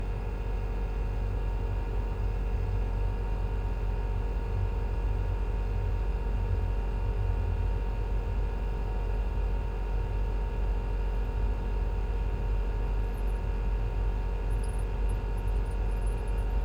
Vehicles, Electric, Car, Tesla, Model 3, Idle, Engine Compartment 03 SND66189.wav